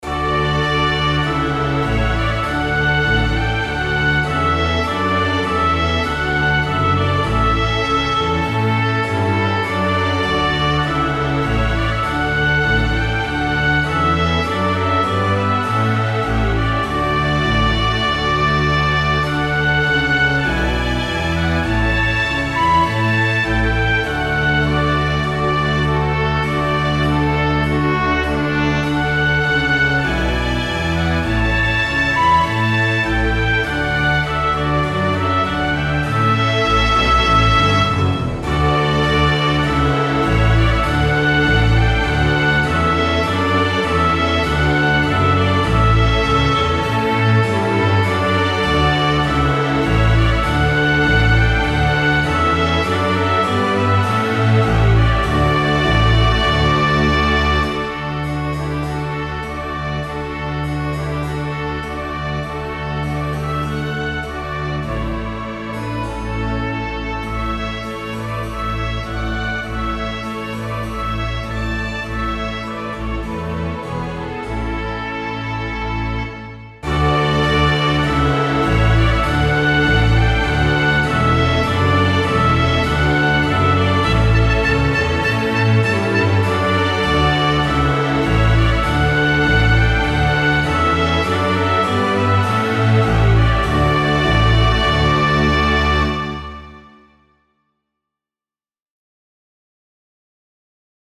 And I removed the damping and predelay from the Notion 3 Reverb on the Master stereo output track but left the reverb and room size as they were (both set to 50).
I worked on it for an hour or two, and I think the current version sounds like something that one might hear as the soundtrack for a BBC mystery show ("Masterpiece Theater" or "Perot"), where the solo trumpet is a bit smoother and in some respects more like a French horn . . .
Regarding the violins, it took me a while to find something that sounds good to my ears, and after listening to the piece for a while, I realized that Violin I actually is playing the same notes as the Solo Trumpet, so it has to be in the same spatial location as the Solo Trumpet, which basically maps to there being just one violin section (Violin II) that is doing something different, so I panned Violin II to the left and balanced it with the Viola, which I panned to the right . . .
I put the Violinchelo in the top center but panned the String Bass across the full range . . .
Done this way, Violin II and Viola create a bit of motion on the left and right, and the Cello ("Violinchelo") and String Bass ("Bass") are there on the bottom with the Cello adding a bit of motion in the middle (top center) . . .
And I panned the Harpsichord the same way as the String Bass, so it appears on the left and right . . .